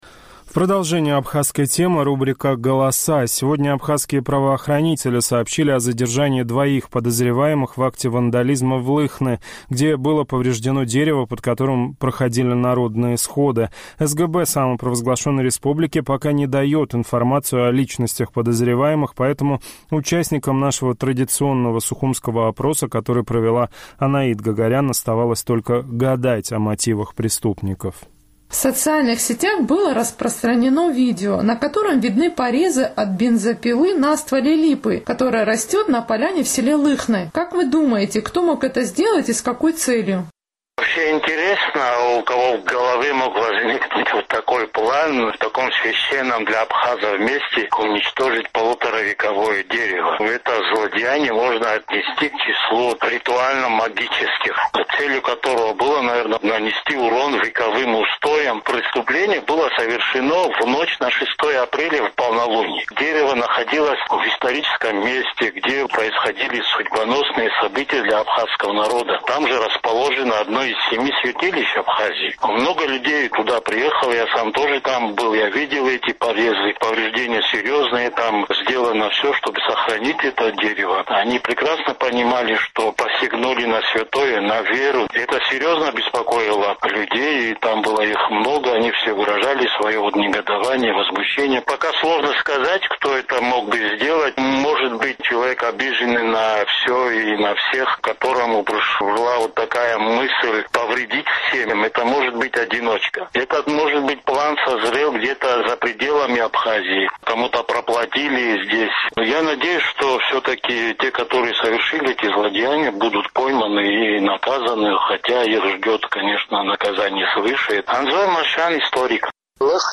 Сухумский опрос – об акте вандализма в Лыхны